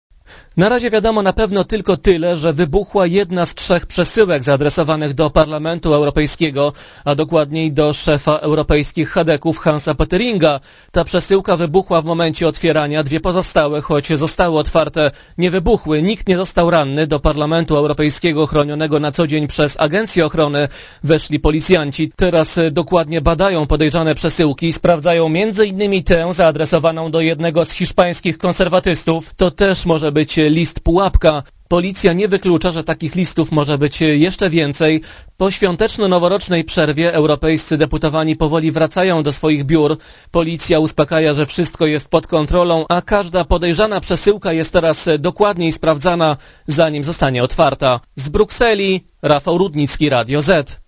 Korespondencja z Brukseli